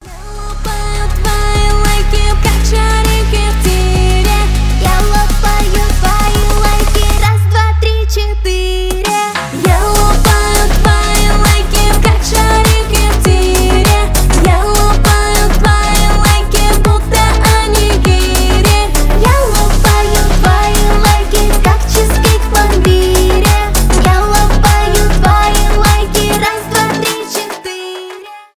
детская музыка
танцевальные
поп